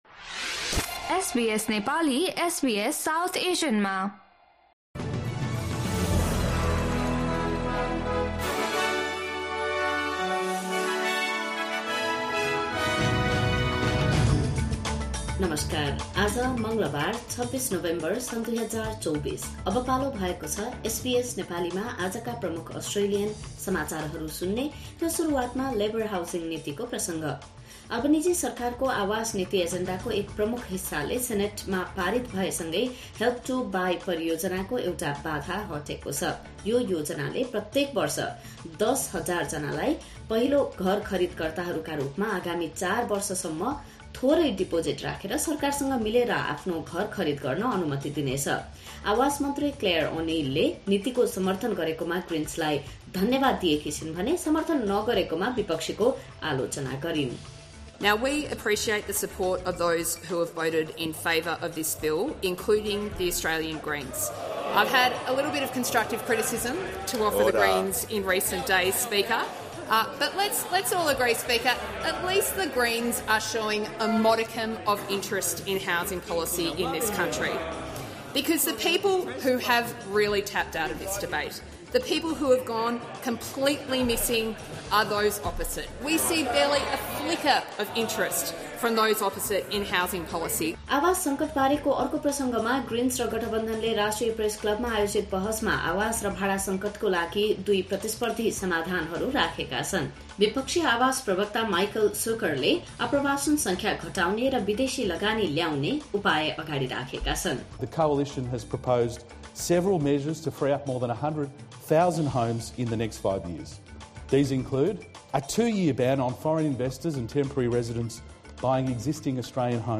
SBS Nepali Australian News Headlines: Tuesday, 26 November 2024